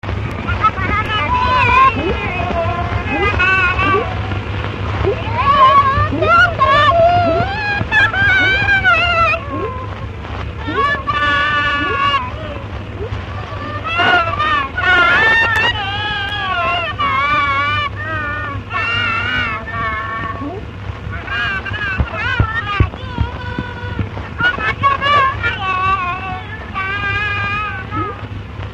Alföld - Békés vm. - Pusztaföldvár
Gyűjtő: Vikár Béla
Stílus: 4. Sirató stílusú dallamok